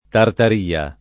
tartar&a; raro